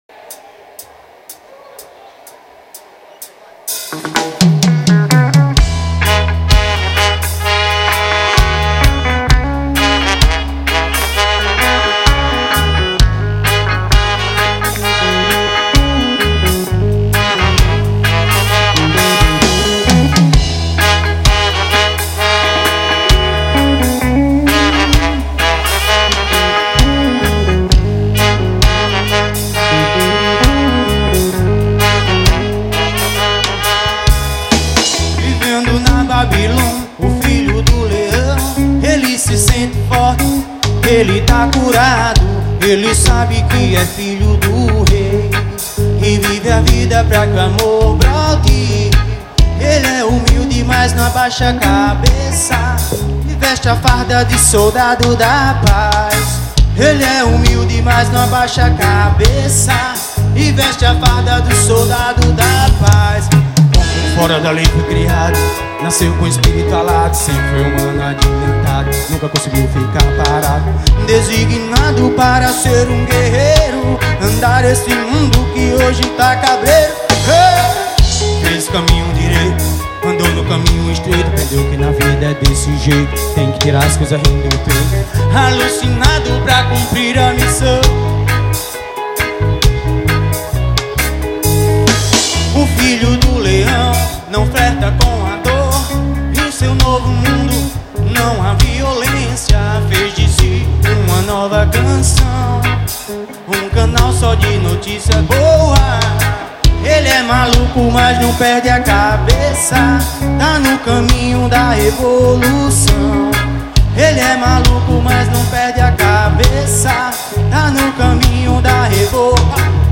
Ao Vivo
Cover